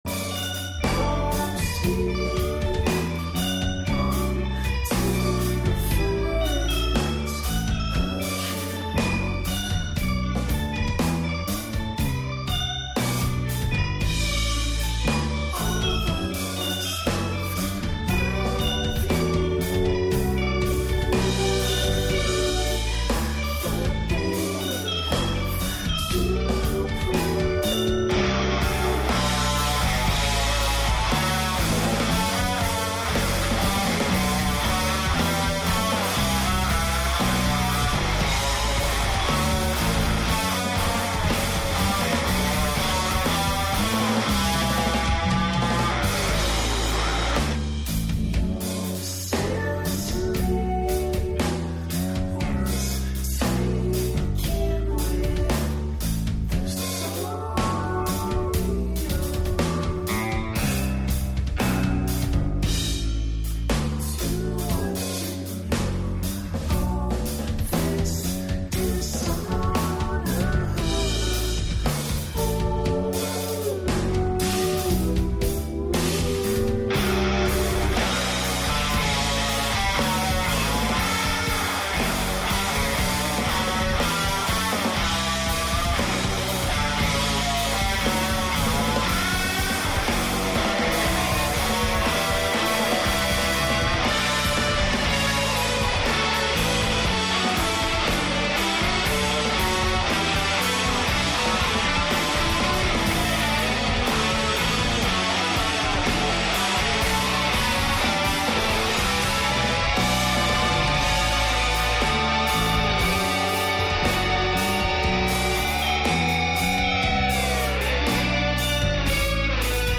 Featuring interviews, acoustic performances of local bands, performance of poetry, and even some audience participation, the Atomic Age is an era for us all.